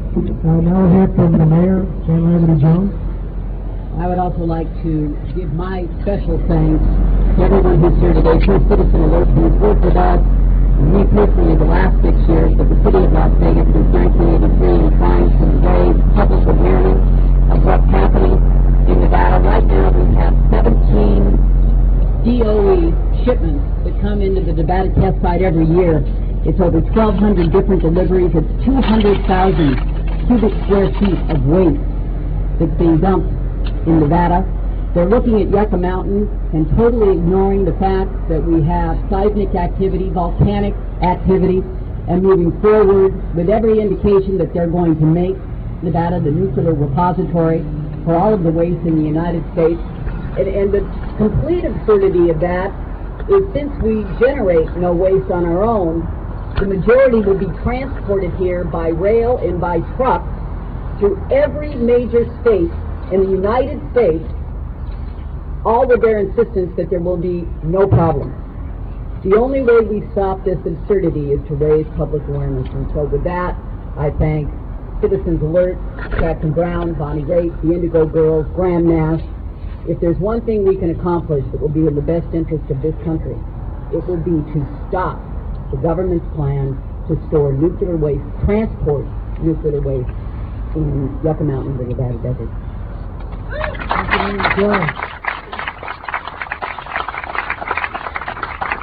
04. press conference - jan jones (1:38)